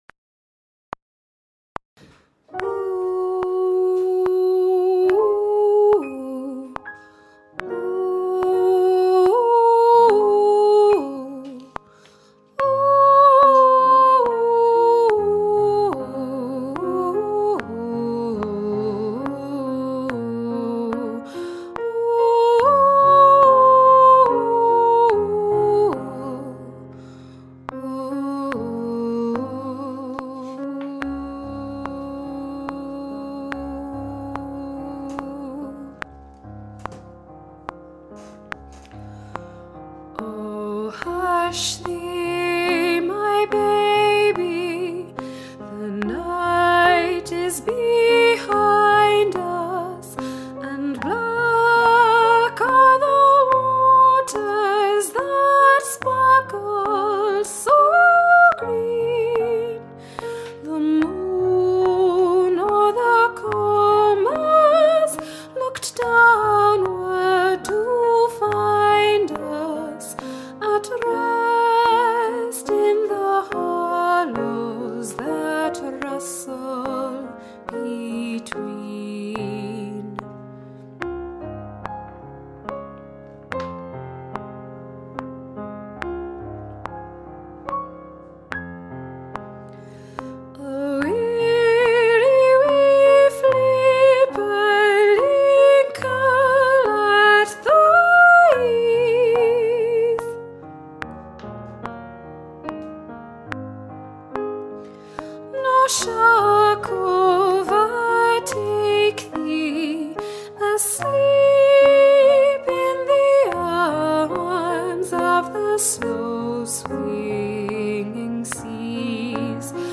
- Œuvre pour chœur à 5 voix mixtes (SATBB) + piano
Soprano Chante